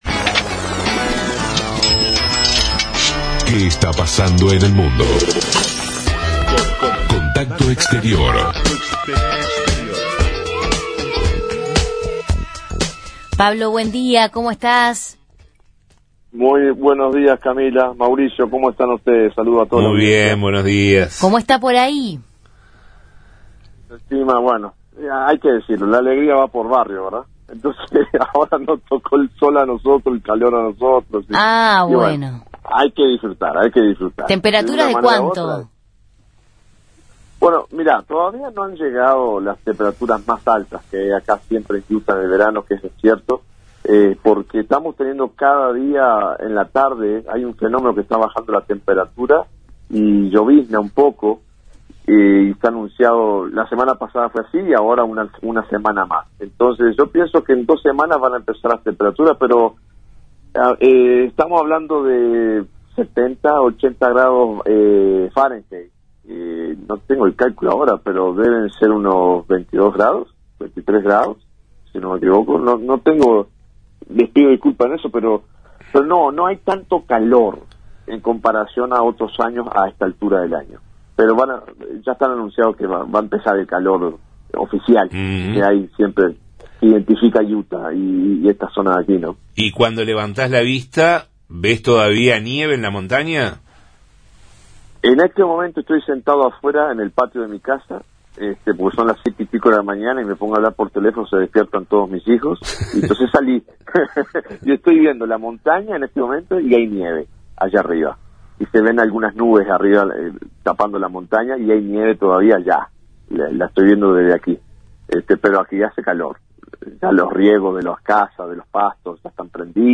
Corresponsal en Estados Unidos